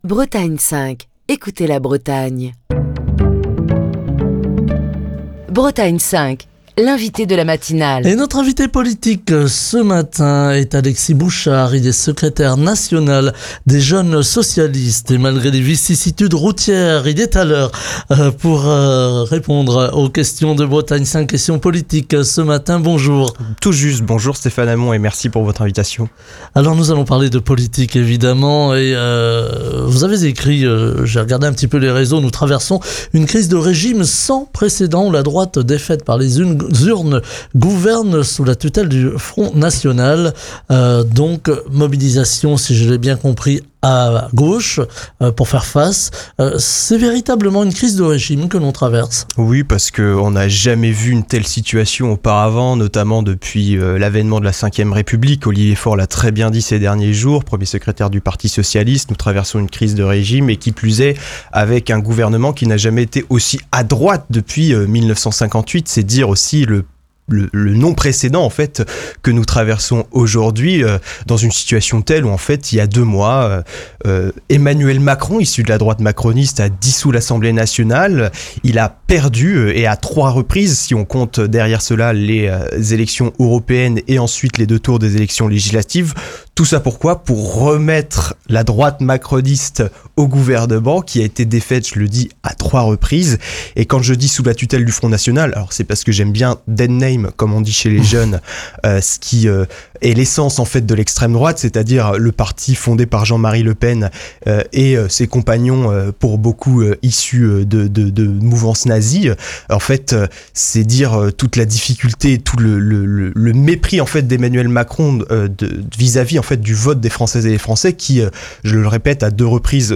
Une situation qui pourrait conduire à une grande incertitude institutionnelle que ne souhaite pas forcément la coalition de gauche. Écouter Télécharger Partager le podcast Facebook Twitter Linkedin Mail L'invité de Bretagne 5 Matin